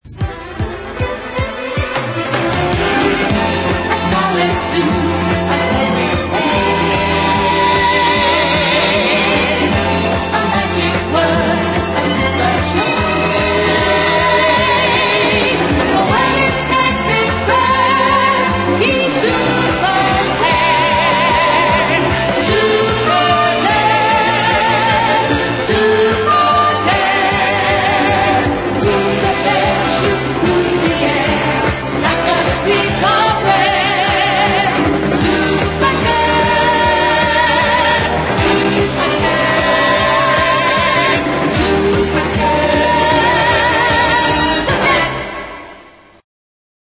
end theme music